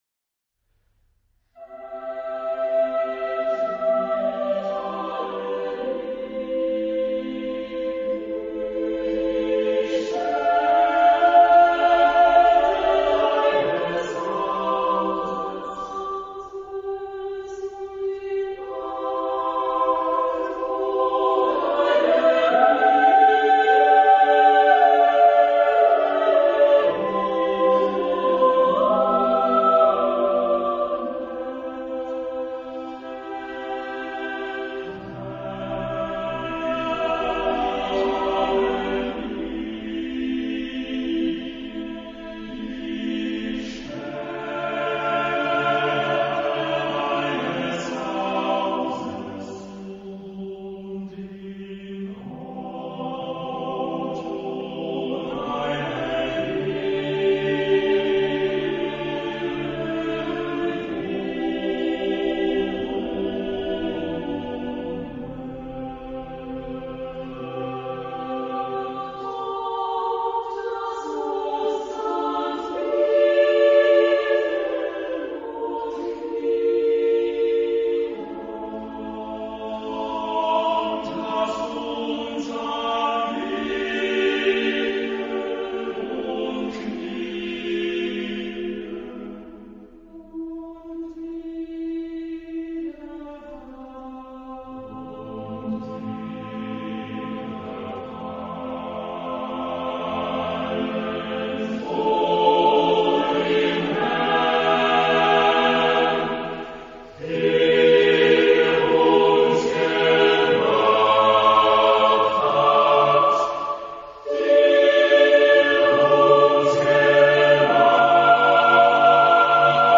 Genre-Style-Form : Sacred ; Romantic ; Psalm
Mood of the piece : adagio
Type of choir : SSATTB (6 mixed voices )
Tonality : C major
Keywords: a cappella ; temple ; church ; Heaven ; Kingdom of God ; Glory ; humility ; Eternal (God) ; alleluia
sung by the Kammerchor Stuttgart, conducted by Frieder Bernius